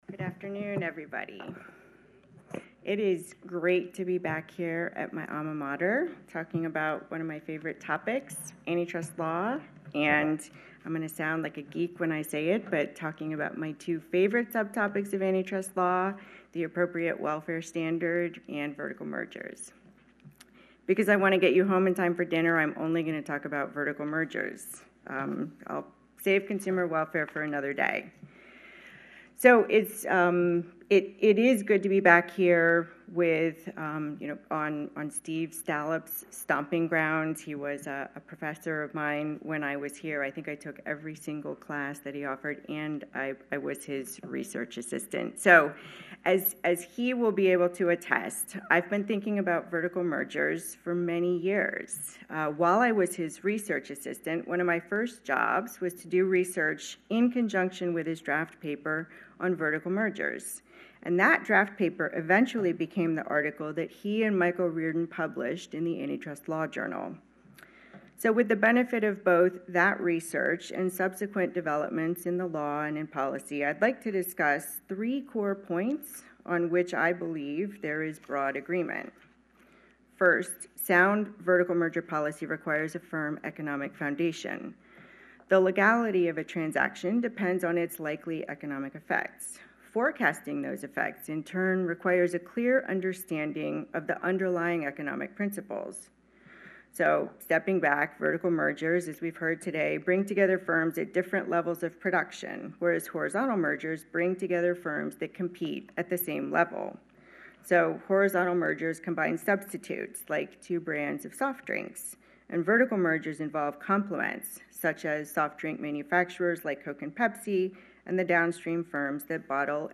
Closing Remarks by FTC Commissioner Christine S. Wilson